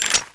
draw.wav